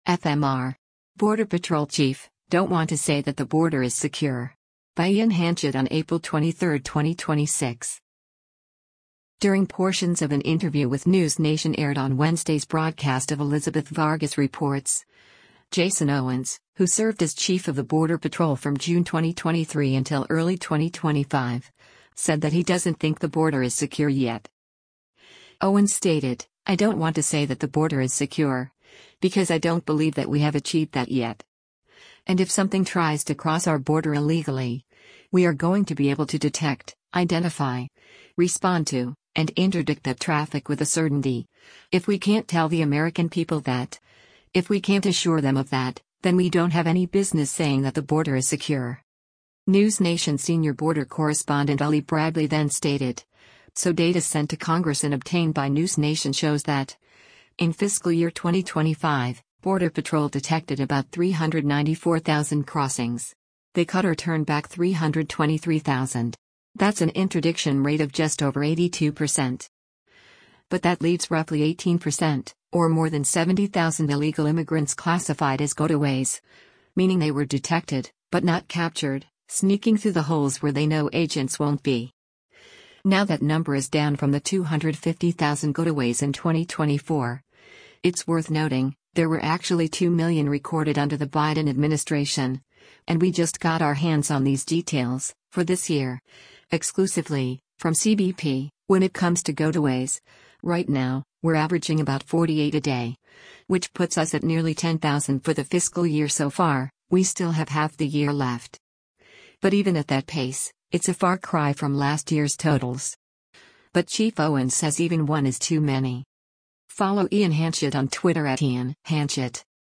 During portions of an interview with NewsNation aired on Wednesday’s broadcast of “Elizabeth Vargas Reports,” Jason Owens, who served as Chief of the Border Patrol from June 2023 until early 2025, said that he doesn’t think the border is secure yet.